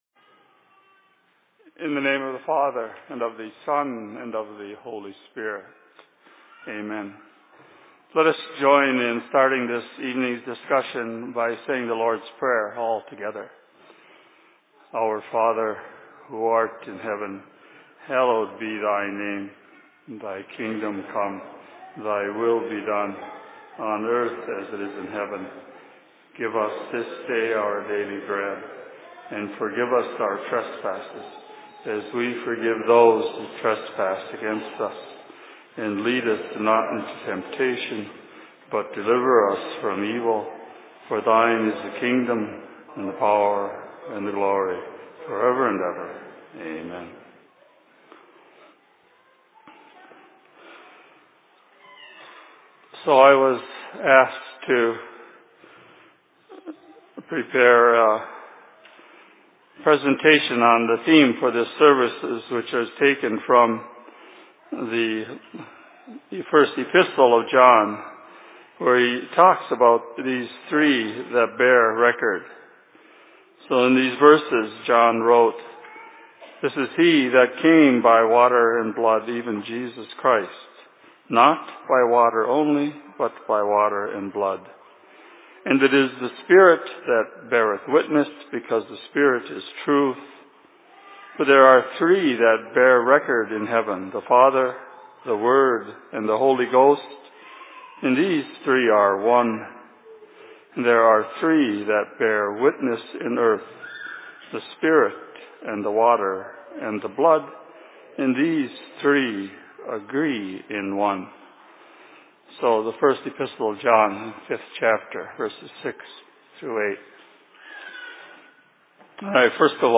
Presentation in Cokato 04.05.2019